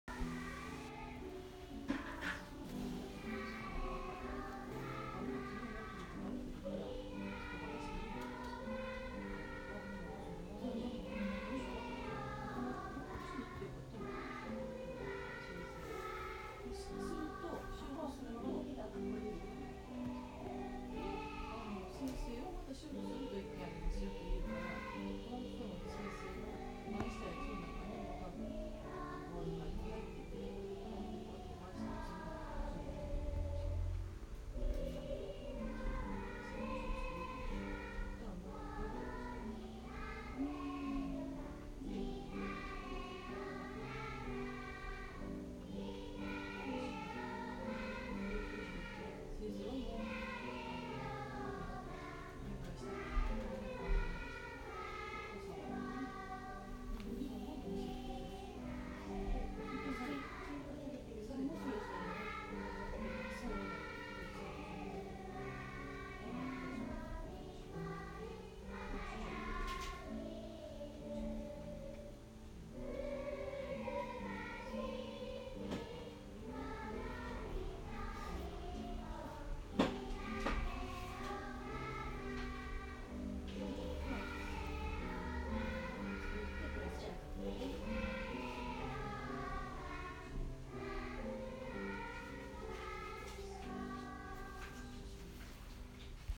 ここまで書いていると聖劇で歌う聖歌が聞こえてきた。